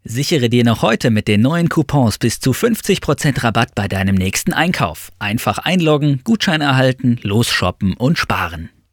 Kein Dialekt
Sprechprobe: Werbung (Muttersprache):
Werbung_2.mp3